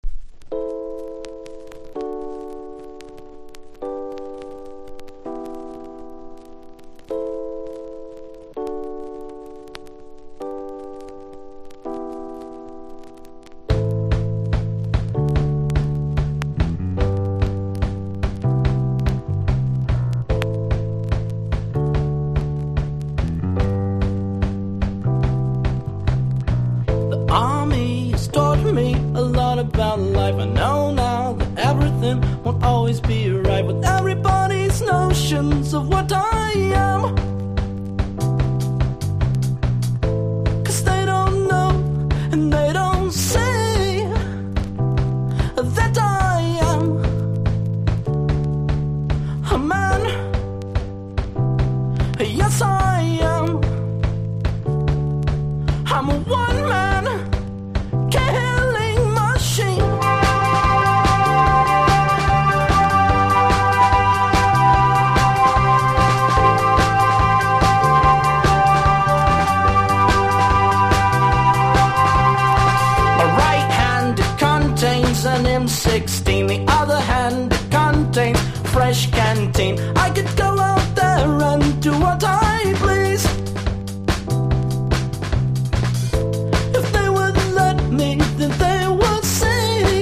アメリカはテネシー出身の5人組ロック・バンド、